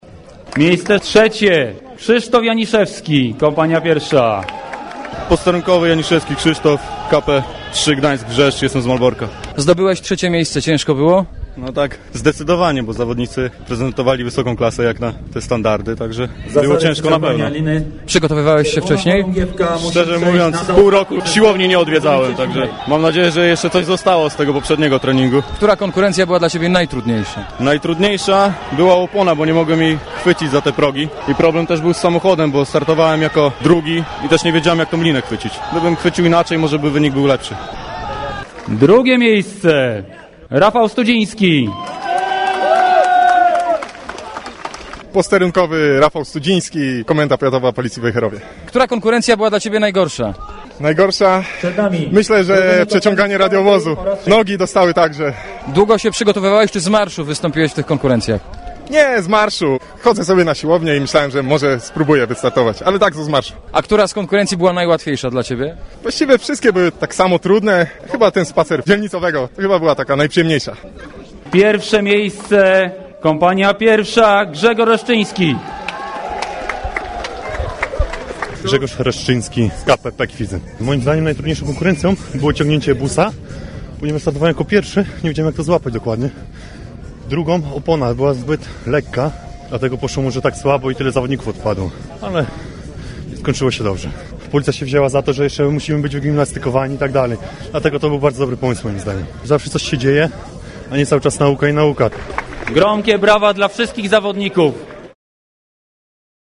Rozmowy ze zwycięzcami konkursu (plik MP3) - plik mp3